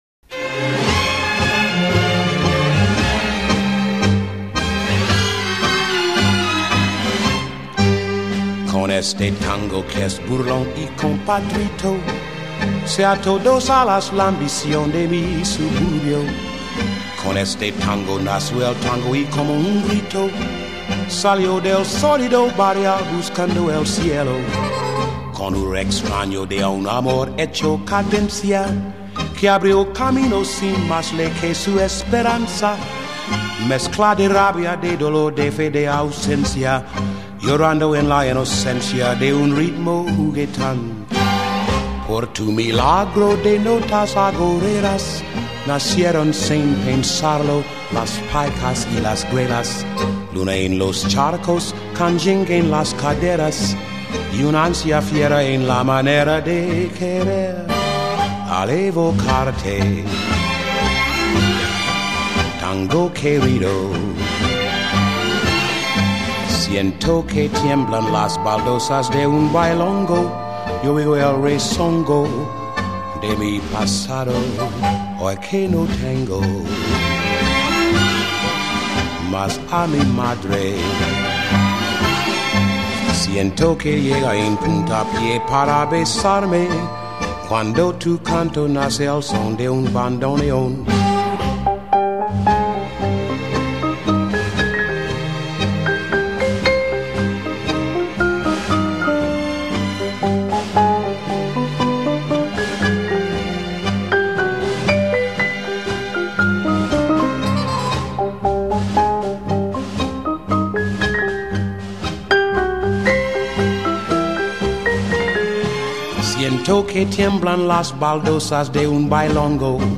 Ballroom Dance